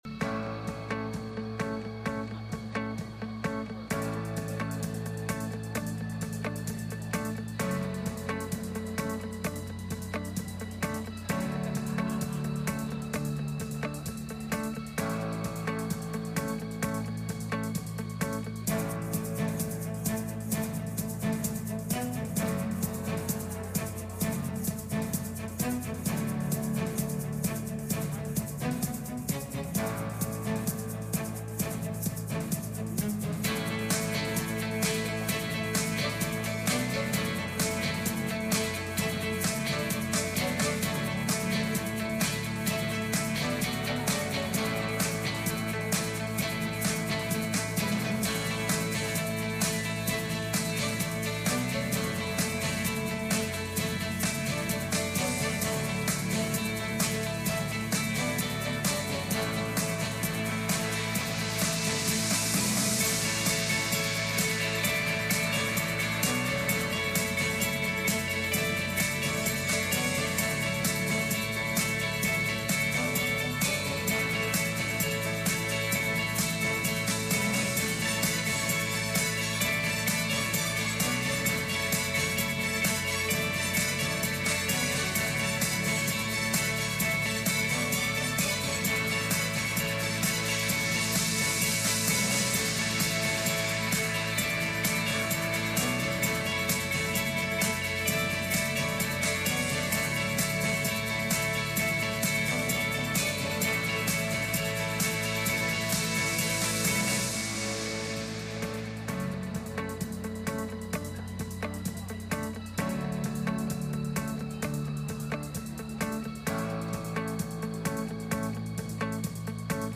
John 8:32-33 Service Type: Sunday Morning « The Dark Room